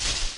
footstep_tall_grass.ogg